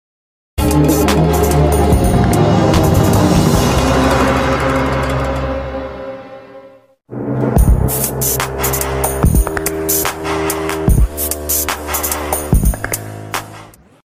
Ferrari 348 ts 1992 . sound effects free download